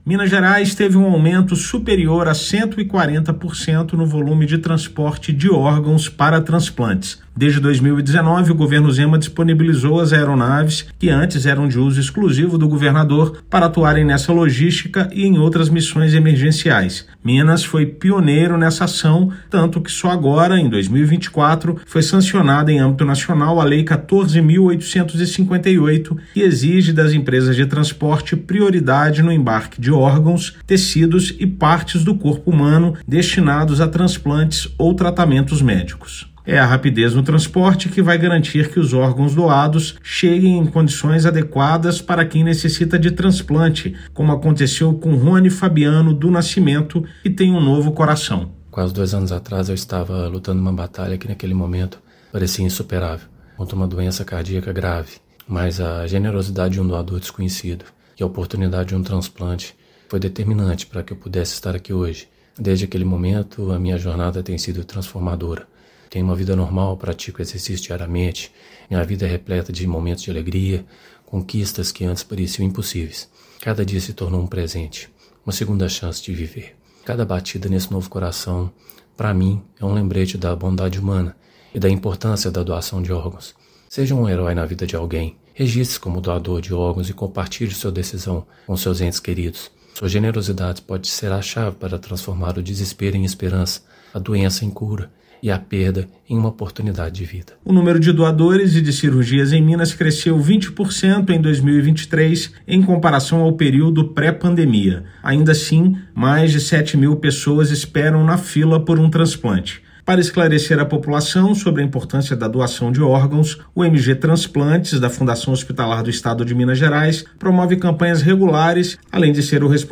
Rapidez no transporte garante que o órgão chegue em condições de ser transplantado ao paciente. Ouça matéria de rádio.